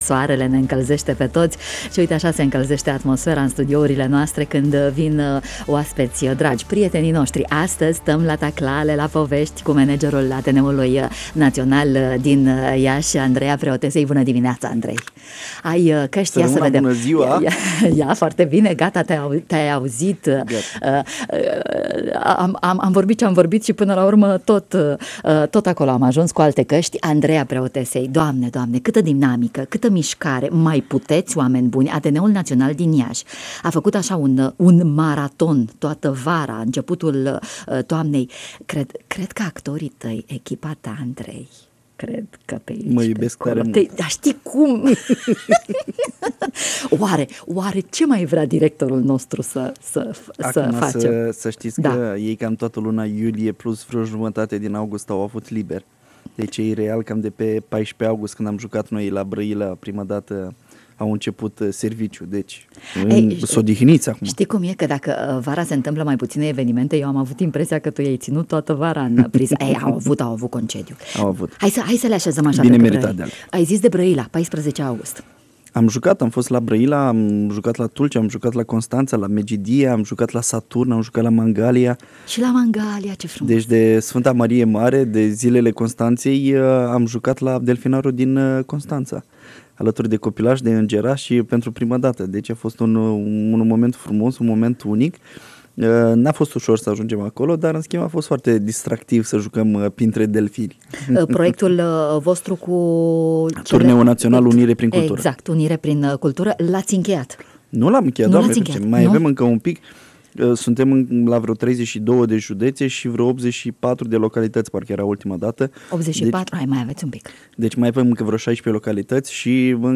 în matinalul de la Radio România Iaşi